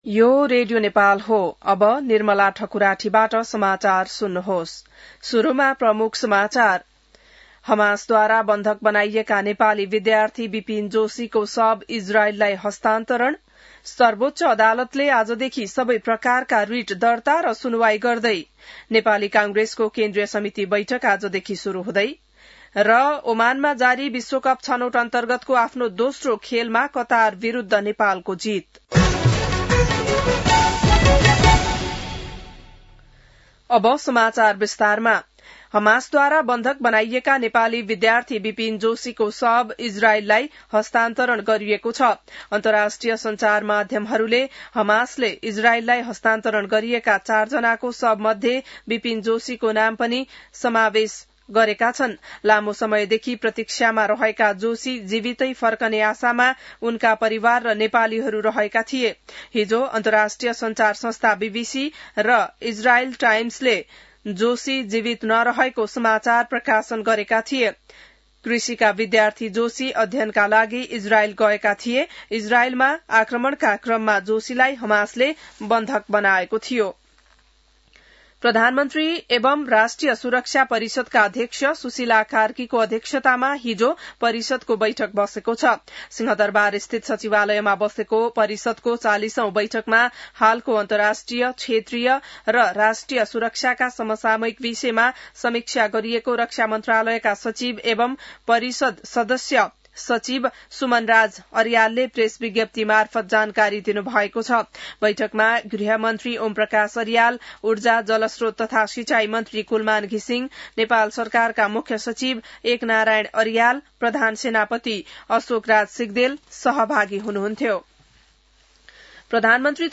An online outlet of Nepal's national radio broadcaster
बिहान ९ बजेको नेपाली समाचार : २८ असोज , २०८२